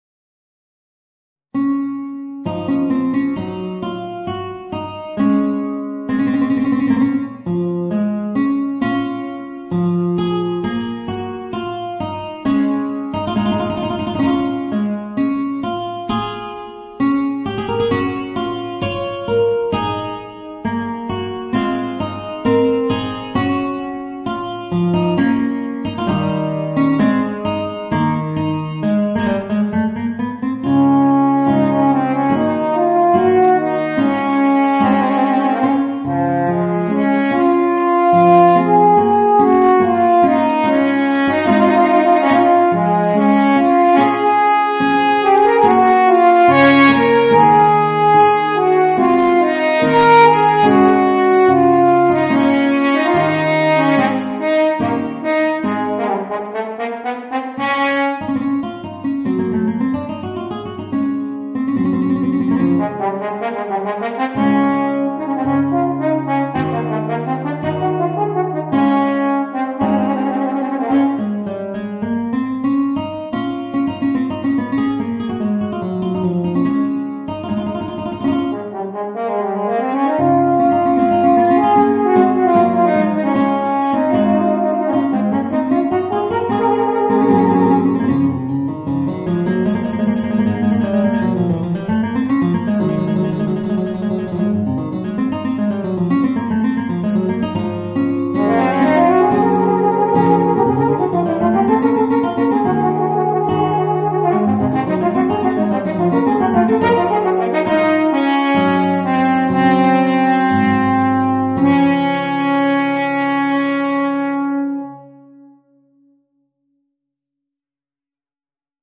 Voicing: Guitar and Horn